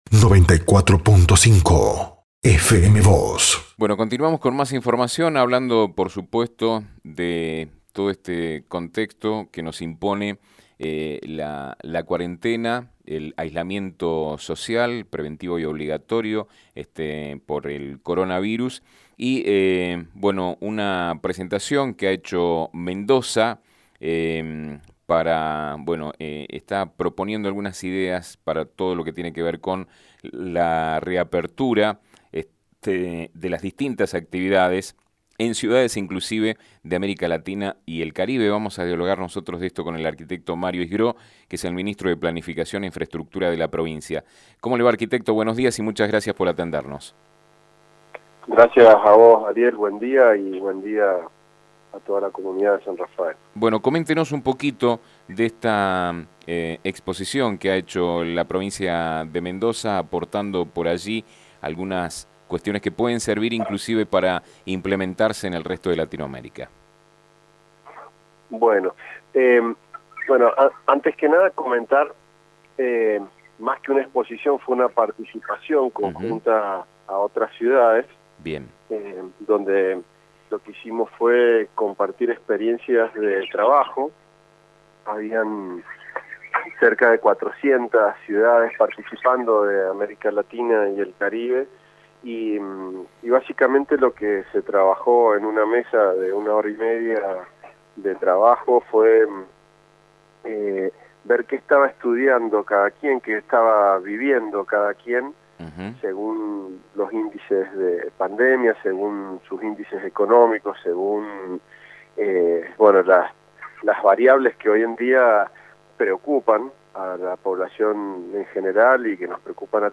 «Mendoza compartió sus experiencias de trabajo junto a otras 400 ciudades de Latinoamérica y el Caribe, más que nada se habló sobre qué estaba viviendo y estudiando cada lugar según las variables que hoy preocupan de la pandemia, como son la salud y la economía. Venimos haciendo un trabajo día a día con los municipios donde intendentes, junto al Gobernador, analizan los DNU de la Presidencia y, en función de ello, se toman acciones concretas para la salida de la cuarentena, por ejemplo, con actividades como la construcción, que genera muchos empleos indirectos», contó el funcionario a FM Vos (94.5) y Diario San Rafael.